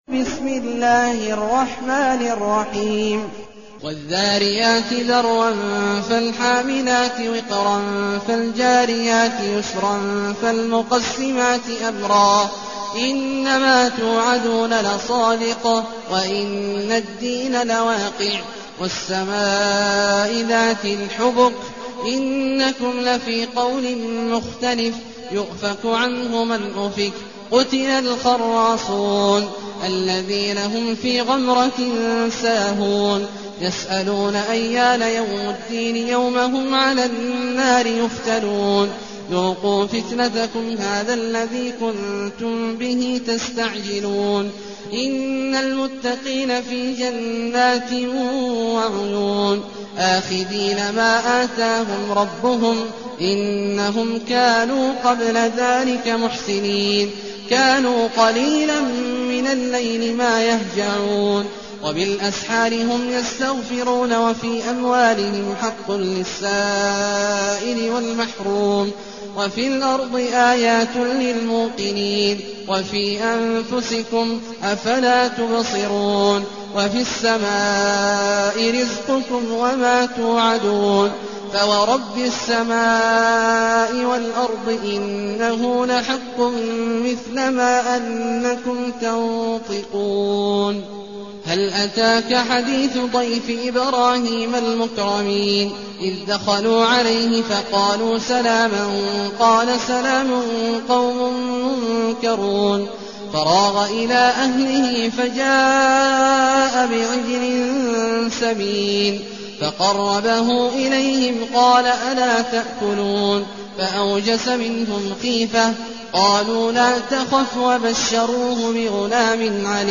المكان: المسجد النبوي الشيخ: فضيلة الشيخ عبدالله الجهني فضيلة الشيخ عبدالله الجهني الذاريات